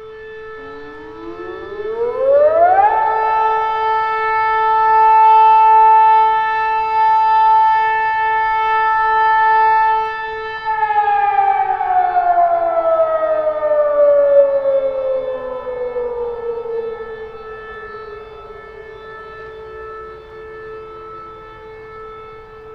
city-alert-siren.wav